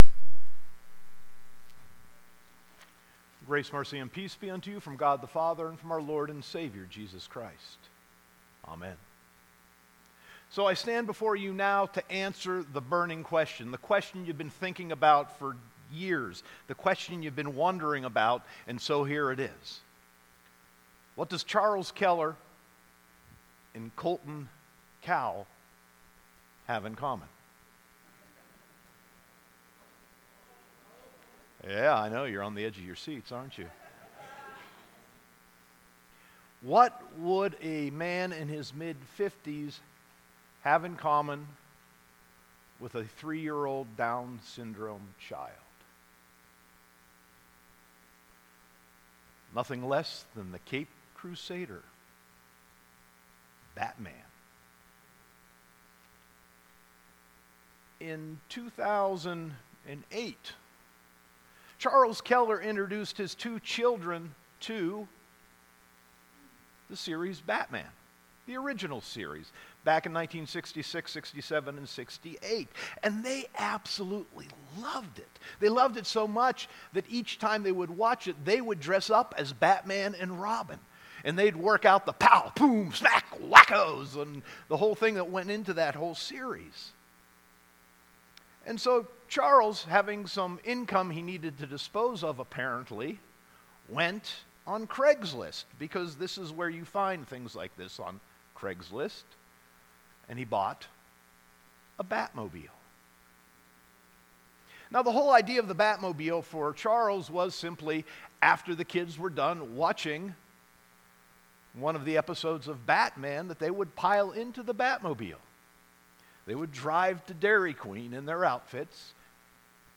Sermon 10.21.2018